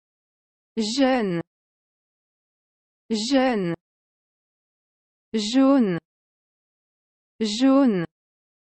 jaune の au は [o] なのでまだ発音できますが、jeune の eu は微妙な口の開きで難しいですよね。
eu は、[オ]と[エ] の中間ぐらいの音で、舌の先を下の歯の裏にくっつけると出しやすいと思います。
【jeune と jaune の音声】
jeune-jaune.mp3